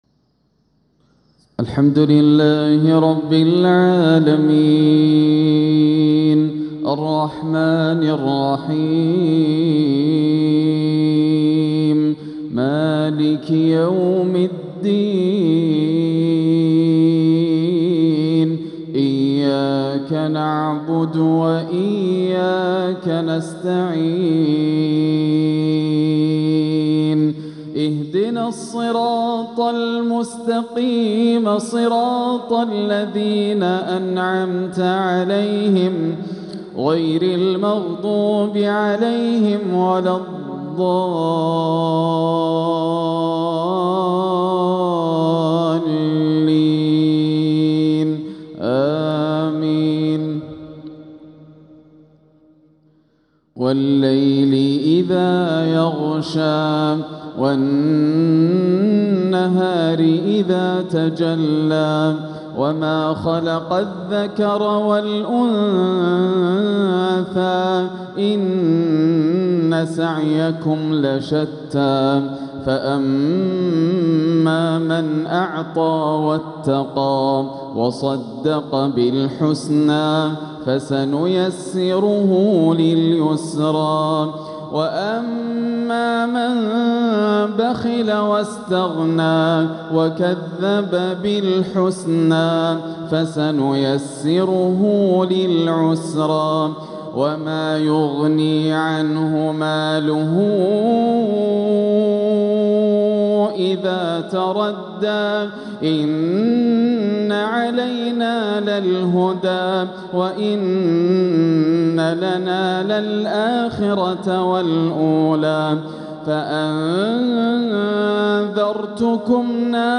تلاوة راائعة لسورتي الليل والتين | مغرب الثلاثاء 10 شوّال 1446هـ > عام 1446 > الفروض - تلاوات ياسر الدوسري